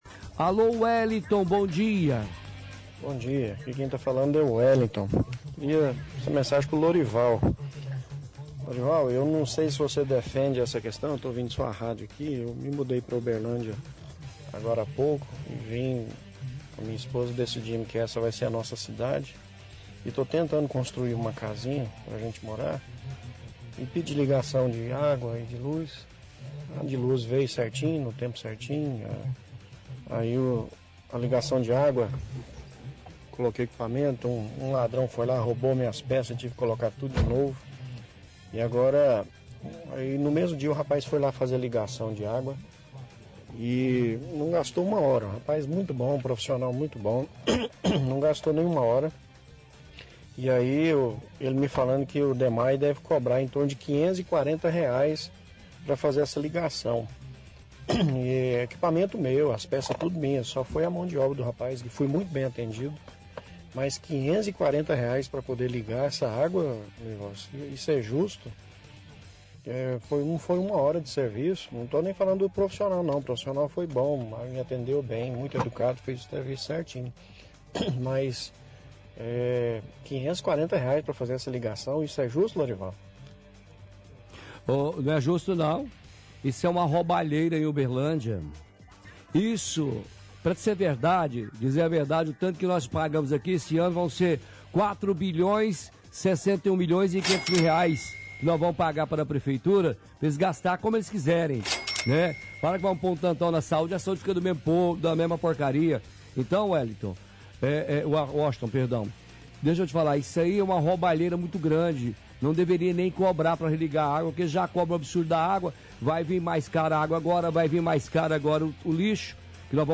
– Ouvinte reclama de taxa de R$ 540 de ligação de água, dizendo que oi profissional não gastou nem uma hora de serviço.